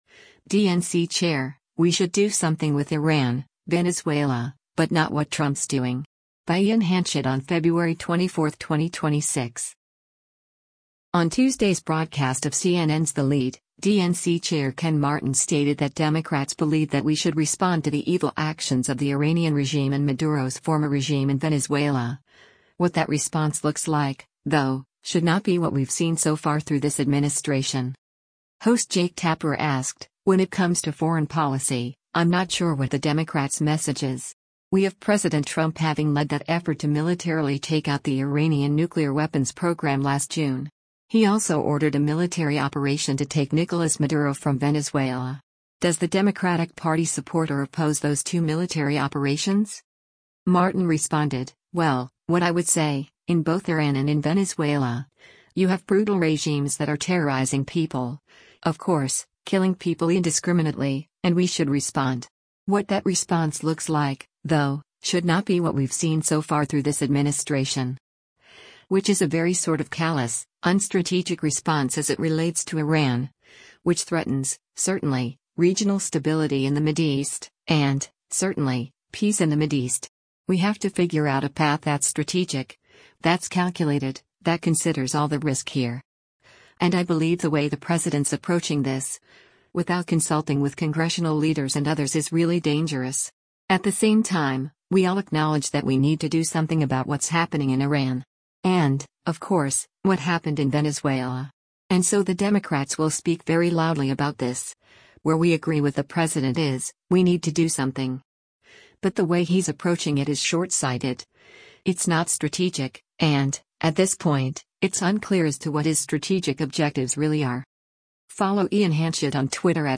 On Tuesday’s broadcast of CNN’s “The Lead,” DNC Chair Ken Martin stated that Democrats believe that “we should respond” to the evil actions of the Iranian regime and Maduro’s former regime in Venezuela, “What that response looks like, though, should not be what we’ve seen so far through this administration.”
Host Jake Tapper asked, “When it comes to foreign policy, I’m not sure what the Democrats’ message is.